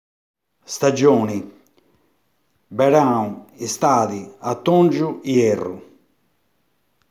ascolta come si dicono i giorno, i mesi e le stagioni nel paese di Senis